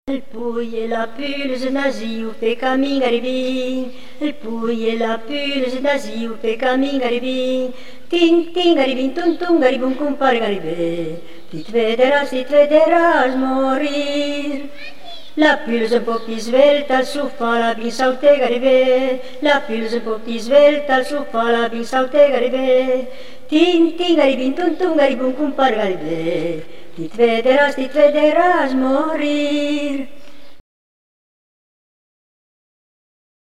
Pidocchio e pulce / [registrata a Barge (CN), nel 1973